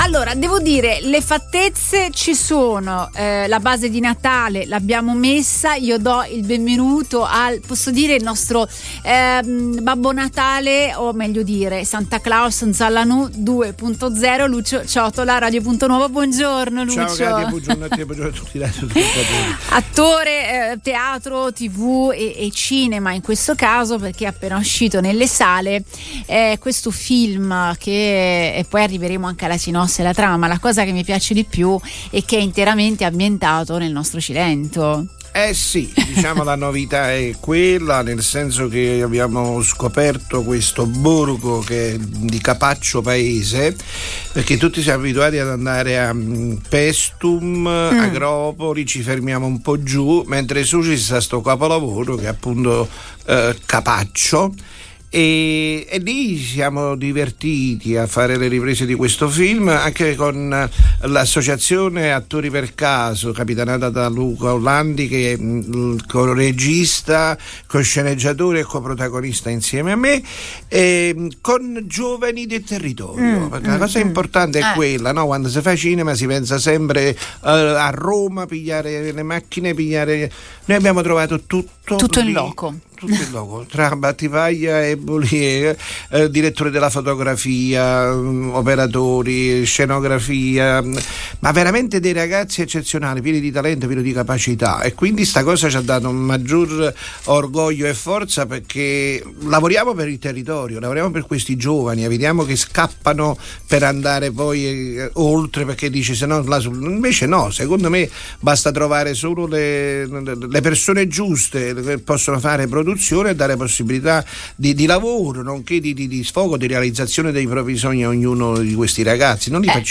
Santa Claus Nzallanú 2.0. Una chiacchierata frizzante, tra risate, aneddoti e qualche riflessione su una storia che parla di tradizioni, tecnologia e magia natalizia in salsa cilentana